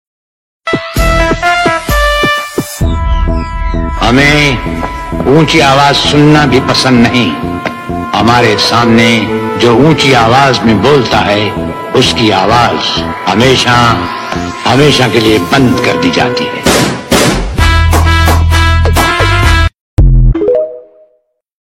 ha hahahahaha sound effects free download